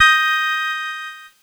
Cheese Chord 21-D#4.wav